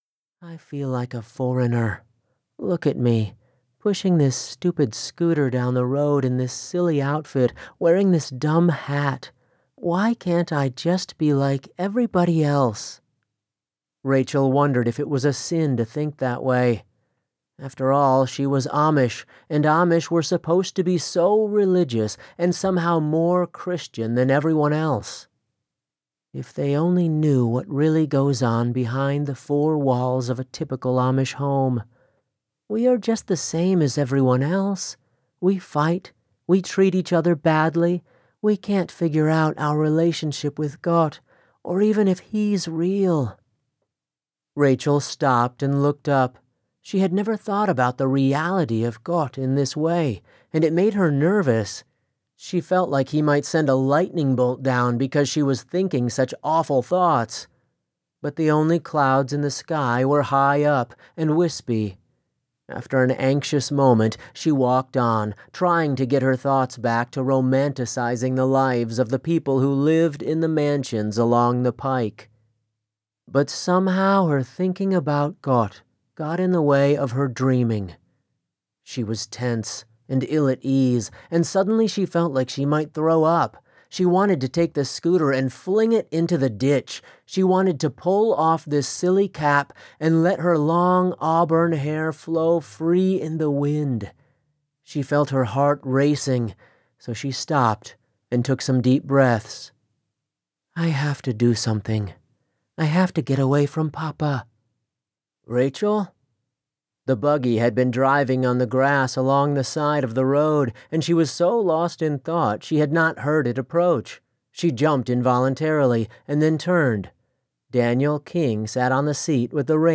A new audiobook that I narrated has just been released!
As a voice over artist, it was a welcome challenge too, as I got to do Italian and French accents, as well as actually speak Italian and Pennsylvania Dutch, which is essentially German.